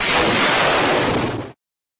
ctf_ranged_rocket_fire.ogg